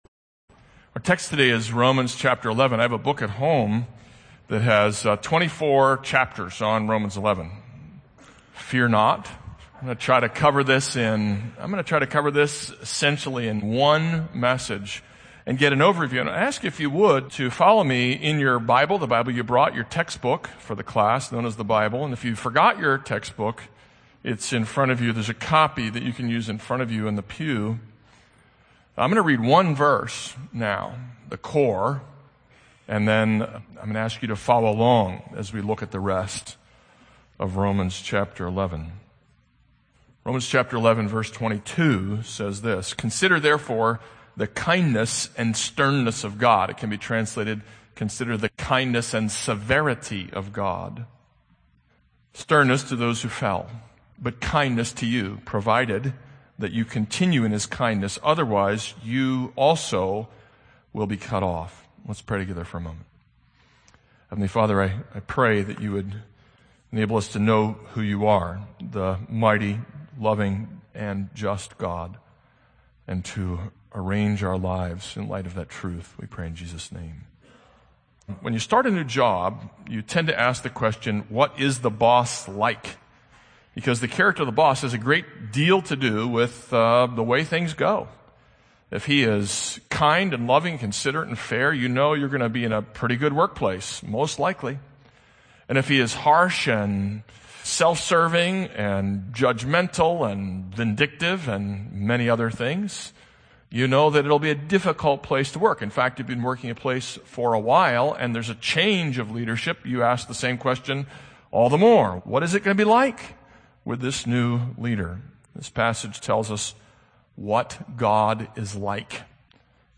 This is a sermon on Romans 11:6-32.